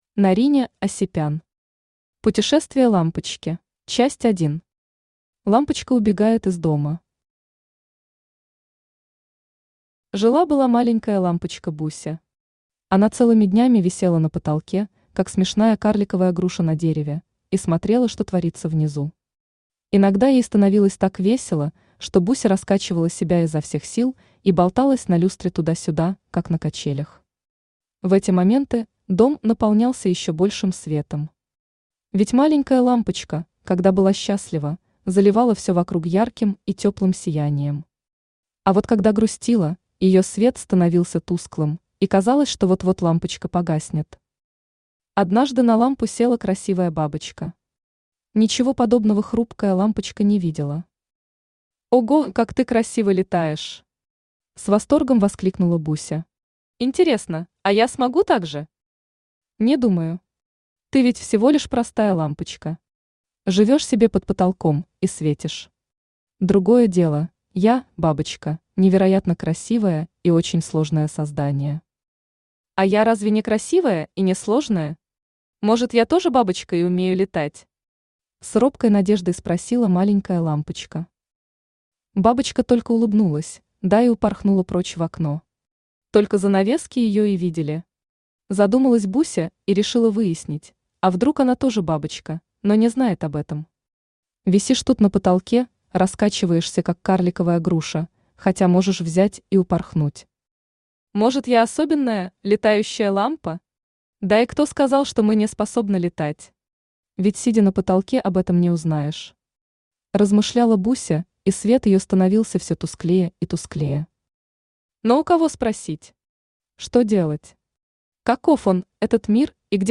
Аудиокнига Путешествие лампочки | Библиотека аудиокниг
Aудиокнига Путешествие лампочки Автор Нарине Размиковна Осипян Читает аудиокнигу Авточтец ЛитРес.